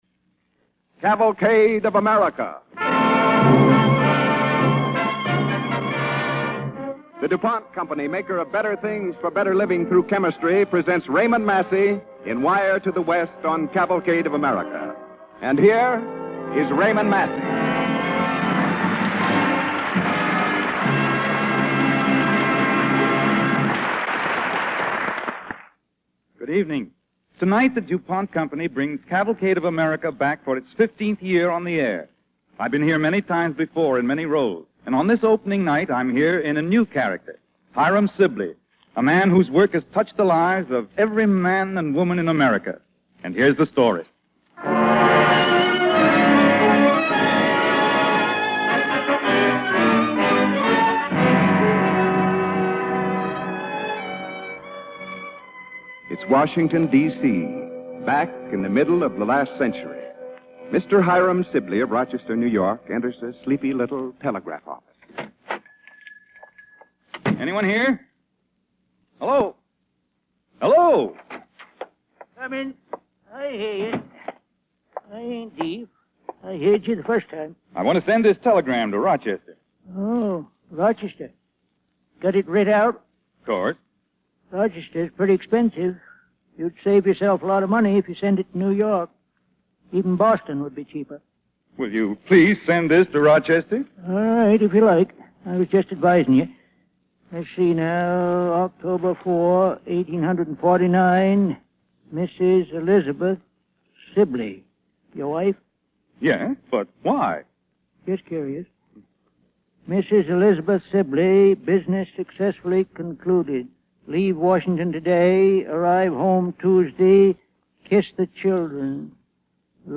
starring Raymond Massey and Parker Fennelly